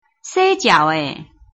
拼音查詢：【四縣腔】jiau ~請點選不同聲調拼音聽聽看!(例字漢字部分屬參考性質)